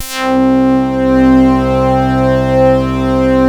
BAND PASS .2.wav